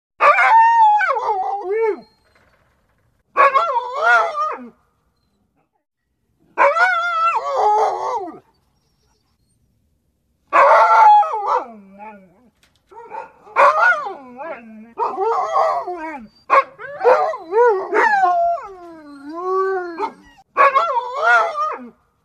Другие рингтоны по запросу: | Теги: Собака, пес